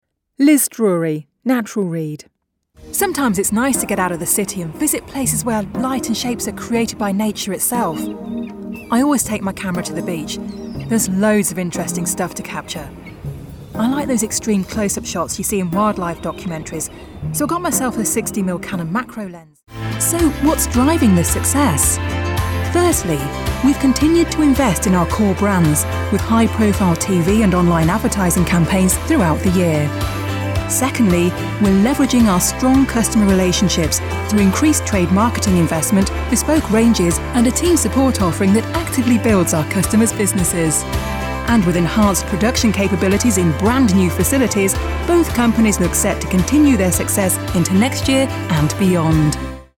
Female
Assured, Authoritative, Confident, Corporate, Engaging, Friendly, Gravitas, Natural, Reassuring, Smooth, Soft, Warm, Witty, Conversational